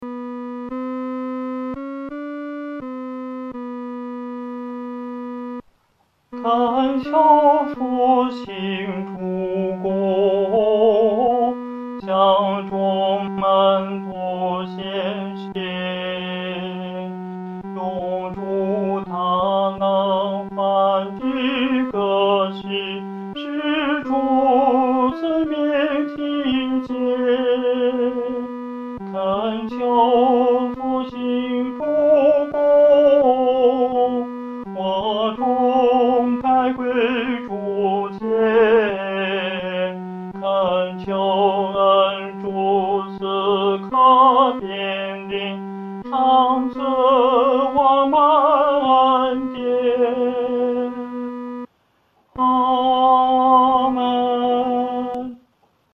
四声 下载